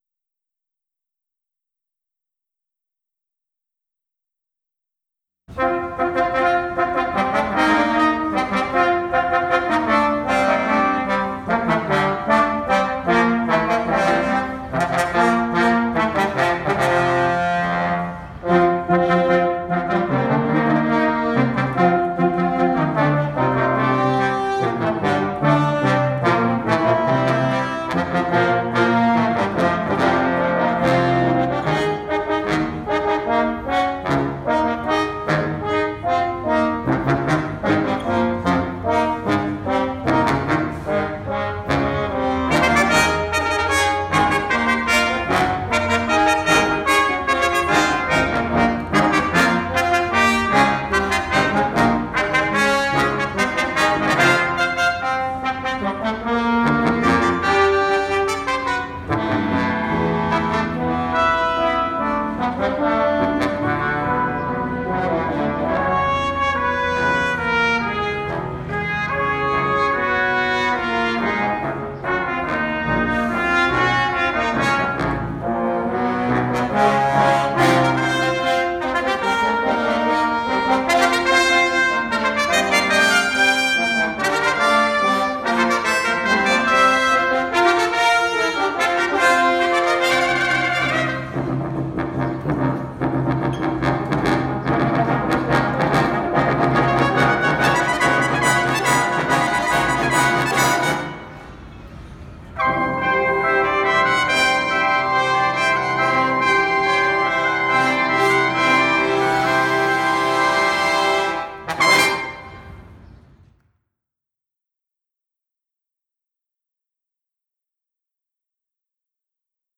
This short fanfare was inspired by the sonics of the LA Phil’s brass section and designed to capture the upbeat, celebratory spirit of the orchestra’s Centennial season.
[recorded on the front steps of Walt Disney Concert Hall]
Brass ensemble